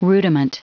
Prononciation du mot rudiment en anglais (fichier audio)
Prononciation du mot : rudiment